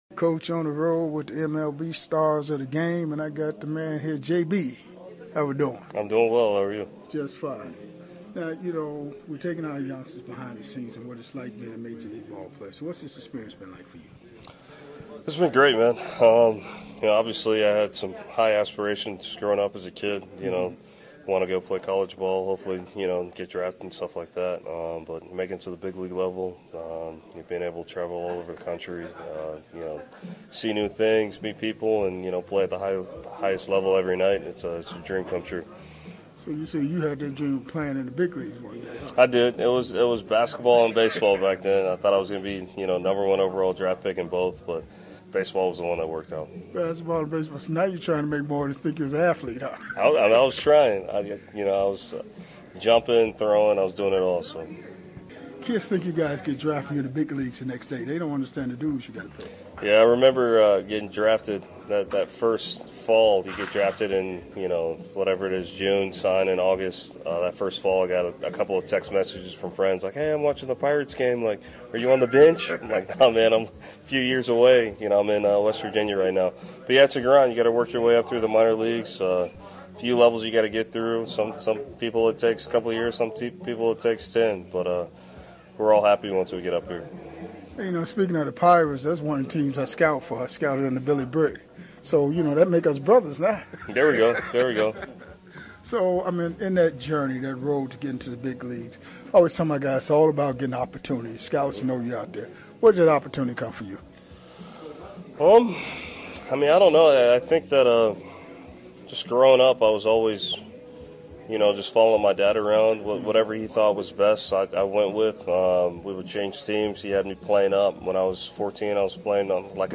one on one with the MLB stars of the game